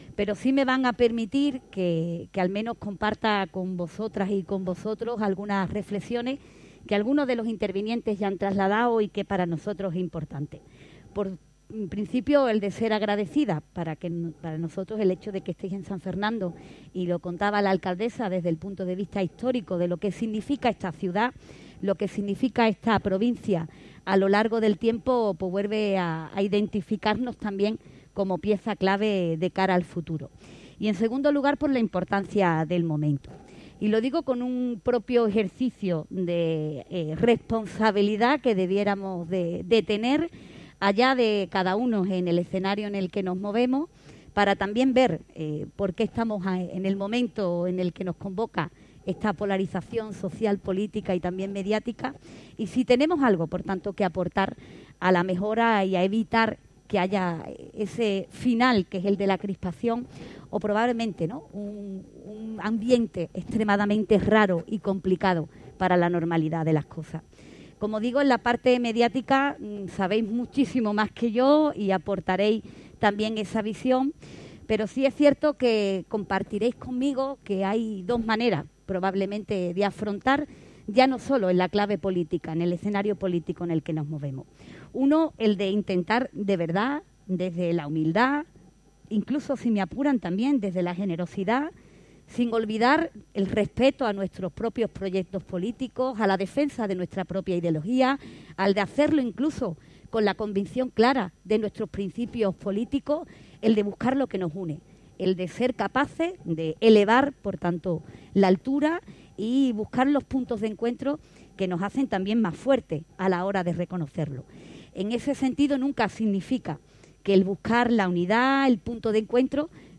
La presidenta participa en la inauguración de las III Jornadas Nacionales de Periodismo organizadas por Publicaciones del Sur